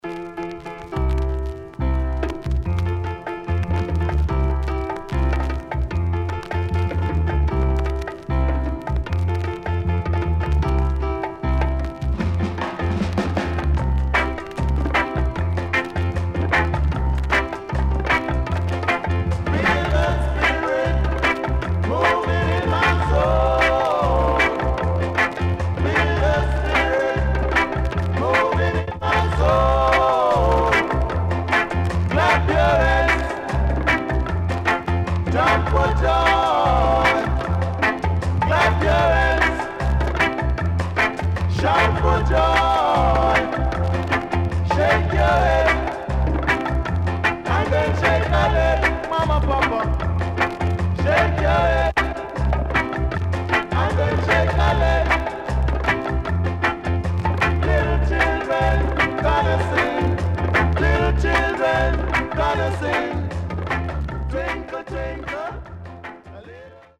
HOME > Back Order [VINTAGE 7inch]  >  EARLY REGGAE
Nice Early Reggae Vocal
SIDE A:所々チリノイズがあり、少しプチノイズ入ります。